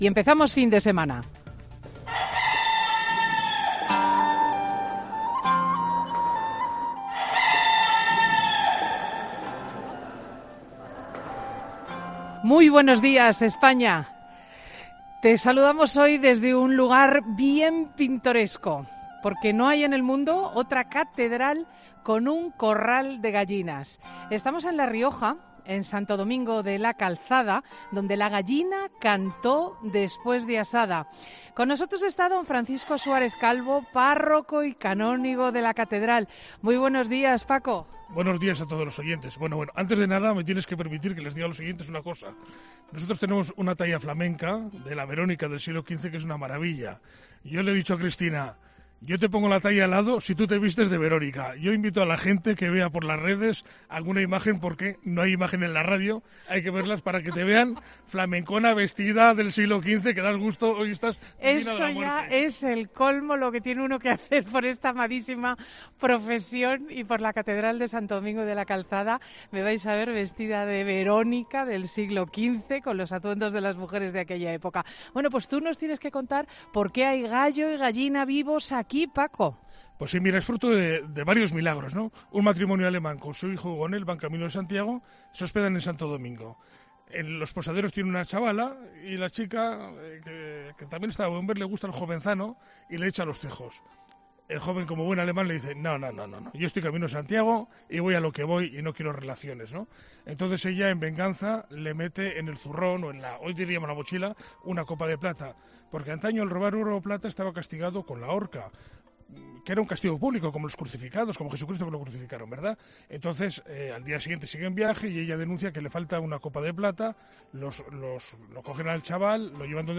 AUDIO: Escucha ahora el monólogo emitido el 19 de mayo, en FIN DE SEMANA .